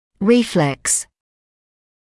[‘riːfleks][‘риːфлэкс]рефлекс; рефлекторный